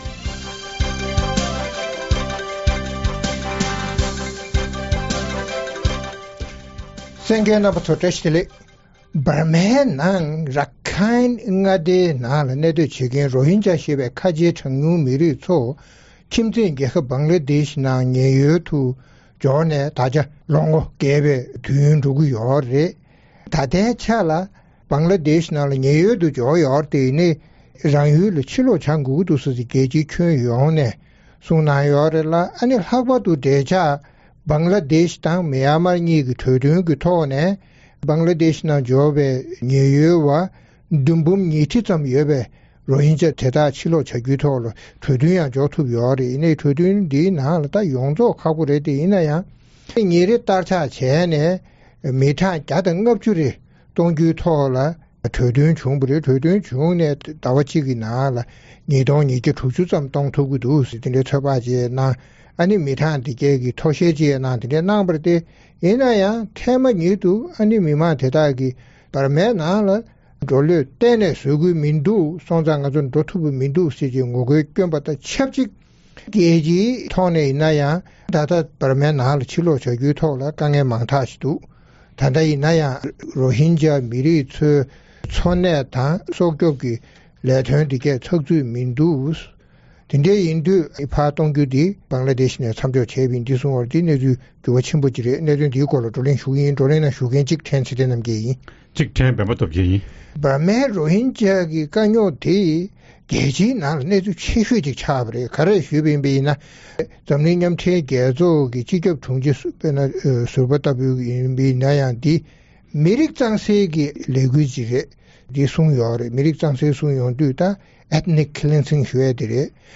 རྩོམ་སྒྲིག་པའི་གླེང་སྟེགས་ཞེས་པའི་ལེ་ཚན་ནང་།འབར་མའི་Rohingya ཞེས་པའི་ཁ་ཆེའི་ཆོས་ལུགས་རྗེས་འབྲངས་པ་བདུན་འབུམ་བརྒལ་བ་རང་ཡུལ་འབར་མ་ནས་Bangladesh རུ་ཉེན་ཡོལ་དུ་འབྱོར་ཡོད་ཅིང་། Rohingay རང་ཡུལ་འབར་མར་ཕྱིར་སློག་གཏོང་ཐབས་བྱས་ཀྱང་Rohingya སྟོང་ཕྲག་གིས་ངོ་རྒོལ་བྱས་ཏེ་རང་ཡུལ་ཕྱིར་ལོག་བྱ་རྒྱུར་ངོས་ལེན་མ་གནང་བ་སོགས་ཀྱི་གནད་དོན་སྐོར་རྩོམ་སྒྲིག་འགན་འཛིན་རྣམ་པས་བགྲོ་གླེང་གནང་བ་གསན་རོགས་གནང་།